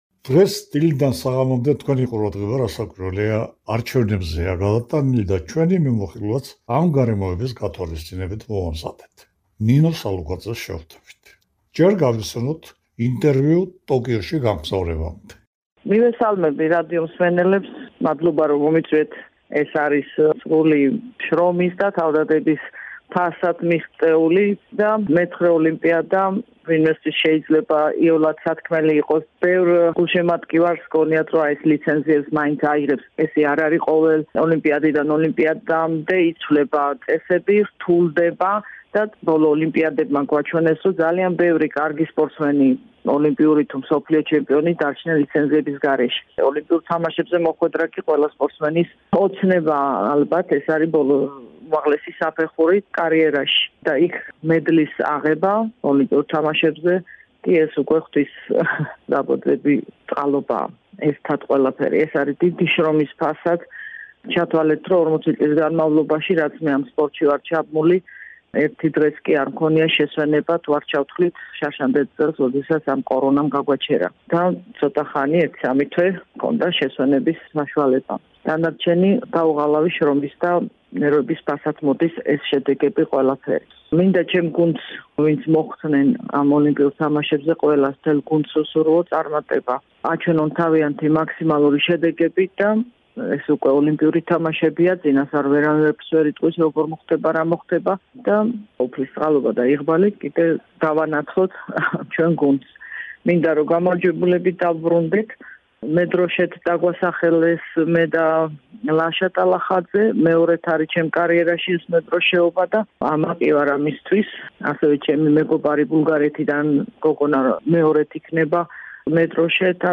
ამ სიტყვებით ამთავრებს ნინო სალუქვაძე ინტერვიუს რადიო თავისუფლებასთან. სახელოვანი სპორტსმენი მეცხრედ გამოვიდა ოლიმპიურ თამაშებზე, ტოკიოშივე გამოაცხადა ასპარეზიდან წასვლის გადაწყვეტილება, თუმცა ახლა, ოლიმპიადამდე პერიოდი პრობლემების გახსენებისას, როცა გაწბილების განცდაც ერთგვარად განელდა -...